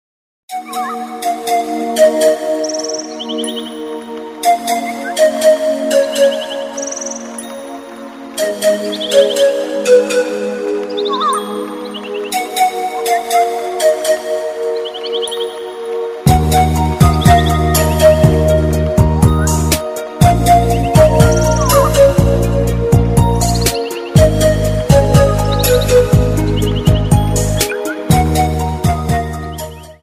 • Качество: 192, Stereo
красивые
спокойные
без слов
релакс
Пение птиц
звуки природы